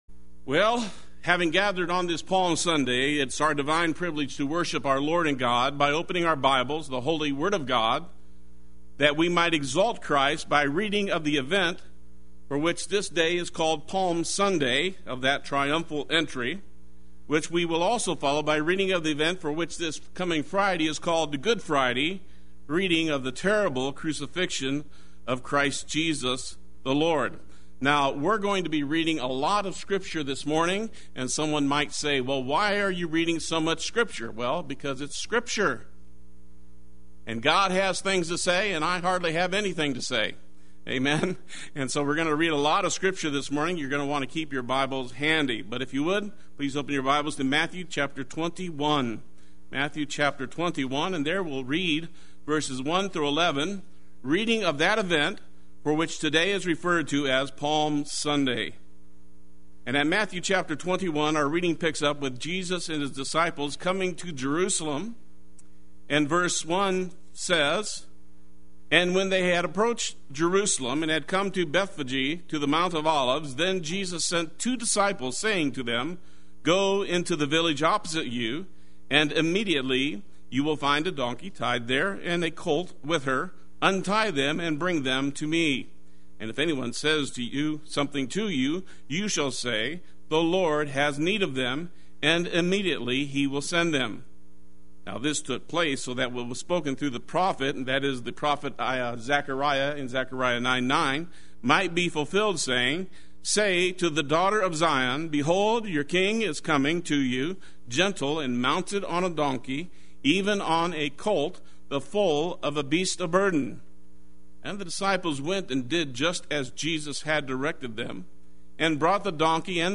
Play Sermon Get HCF Teaching Automatically.
By His Scourging We Are Healed Sunday Worship